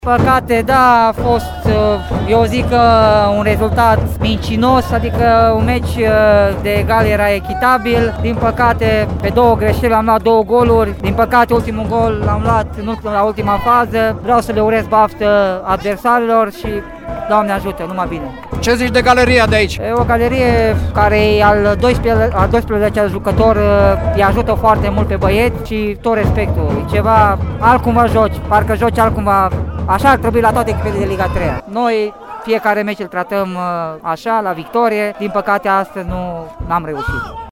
Ascultăm reacții culese, după joc